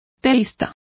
Complete with pronunciation of the translation of theists.